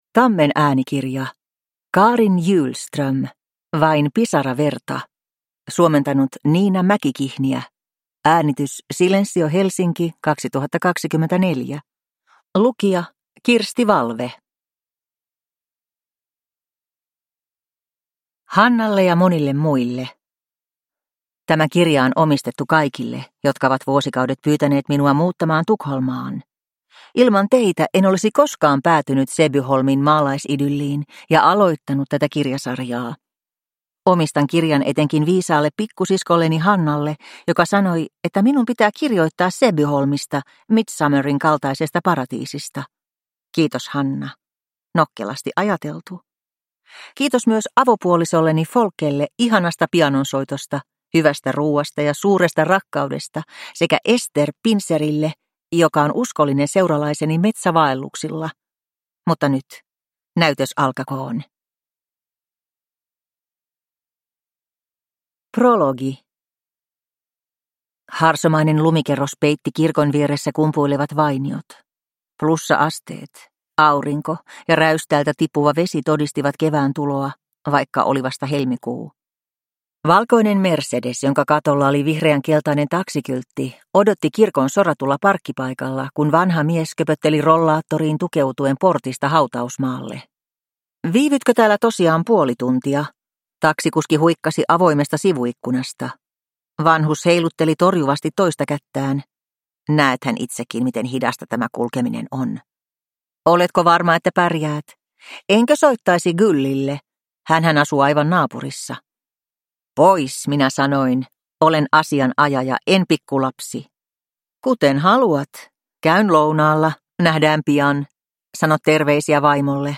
Vain pisara verta (ljudbok) av Carin Hjulström